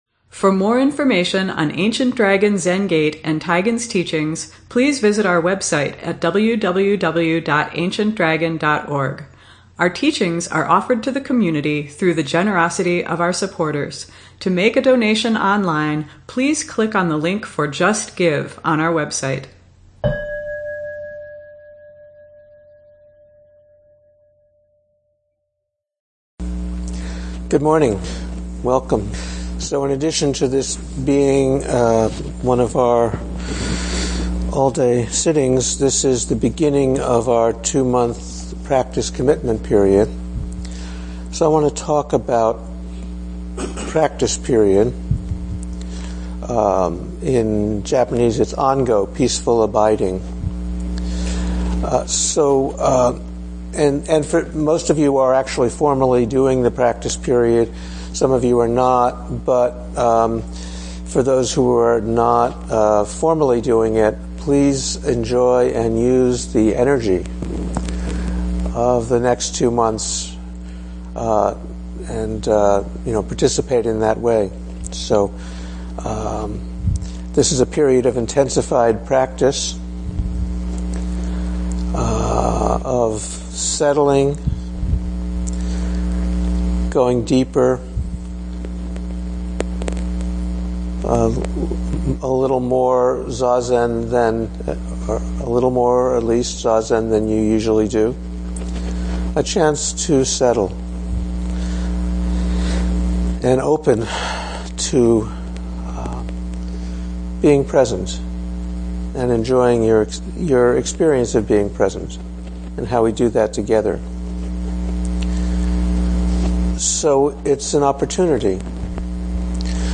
ADZG 434 ADZG Sunday Morning Dharma Talk